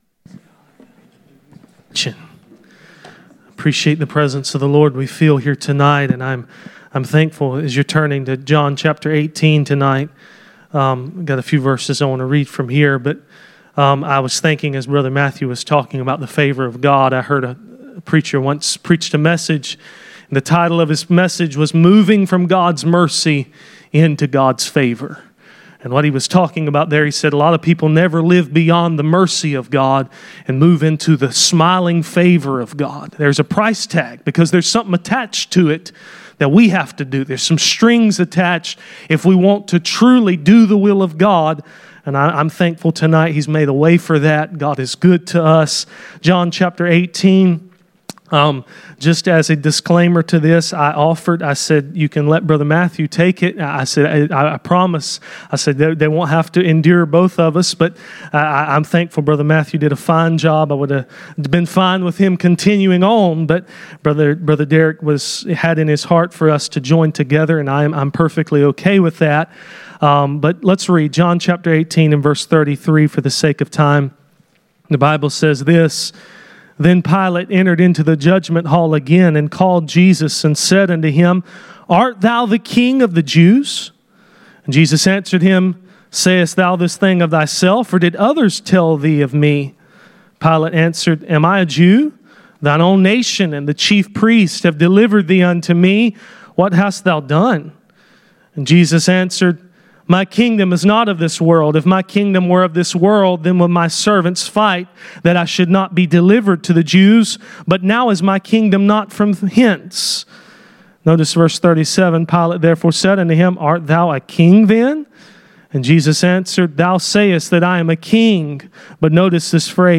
Passage: John 18:33-19:5 Service Type: Sunday Evening